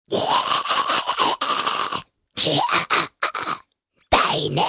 Download Gremlin sound effect for free.